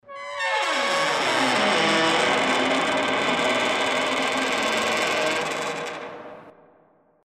Звуки хлопанья дверью
Дверь скрипит при открывании